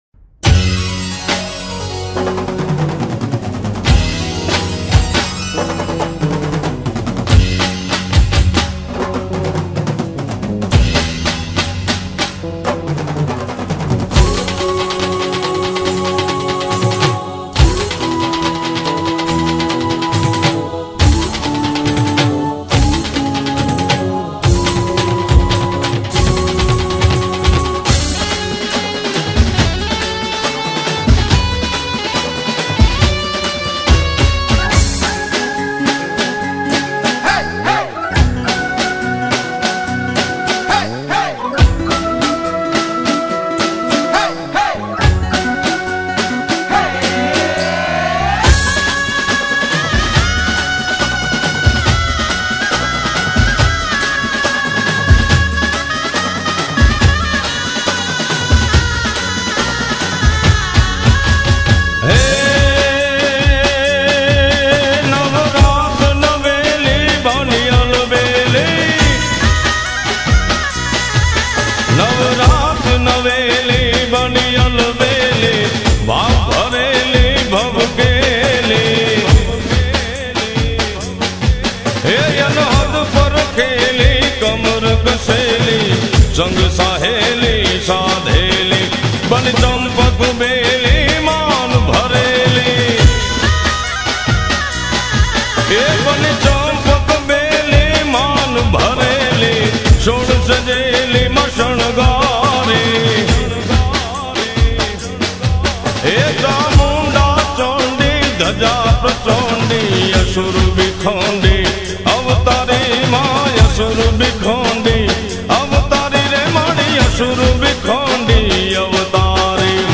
Nonstop Dandiya